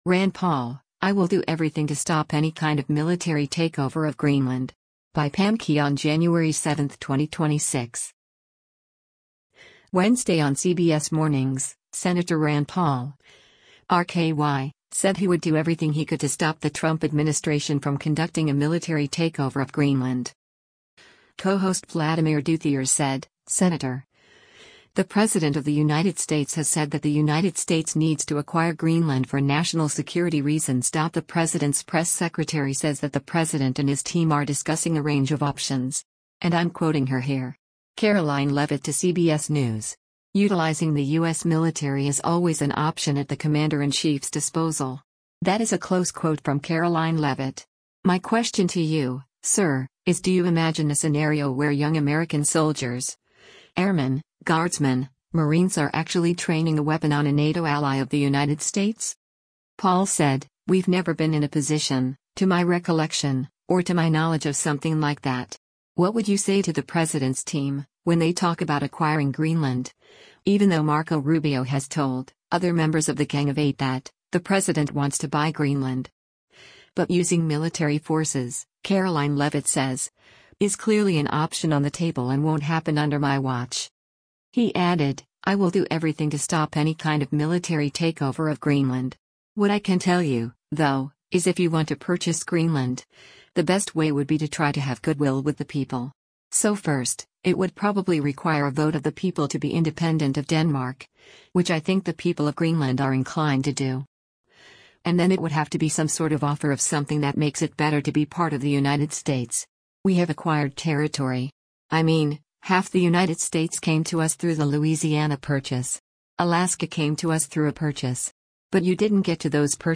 Wednesday on “CBS Mornings,” Sen Rand Paul (R-KY) said he would do everything he could to stop the Trump administration from conducting a military takeover of Greenland.